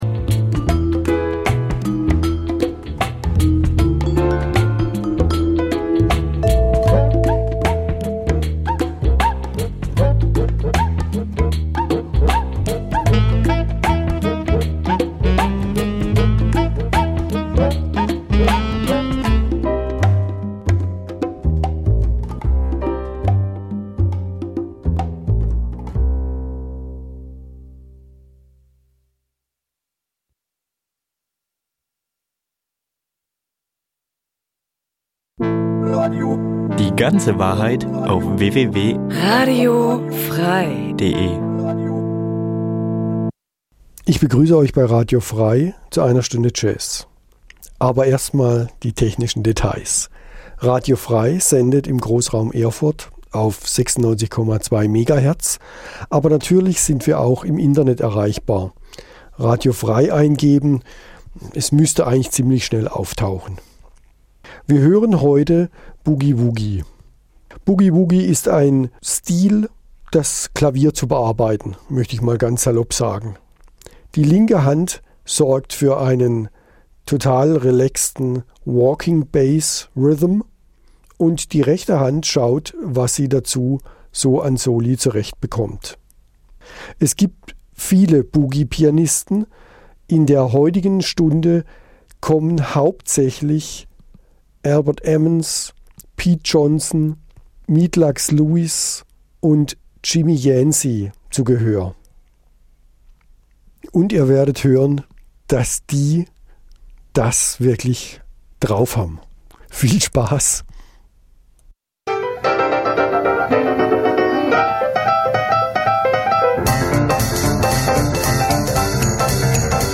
Boogie-Woogie - am Klavier 2 (circa 1940)
Eine Stunde Jazz Dein Browser kann kein HTML5-Audio.